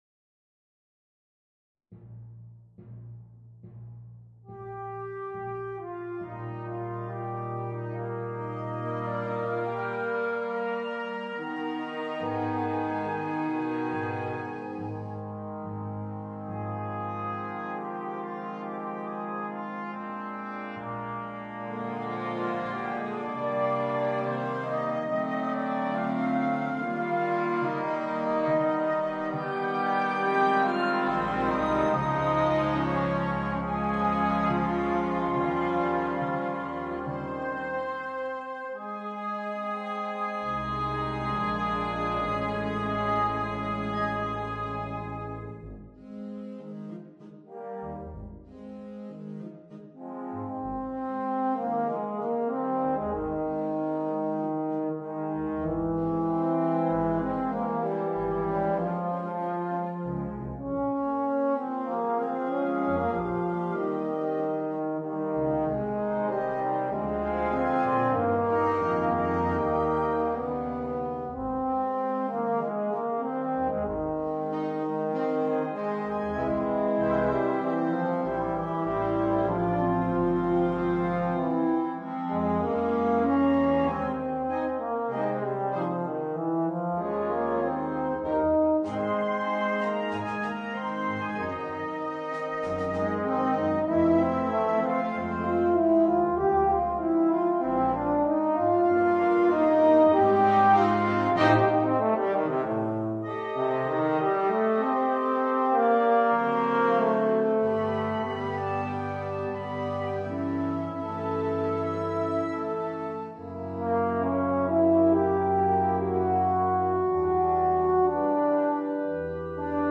eufonio solista e banda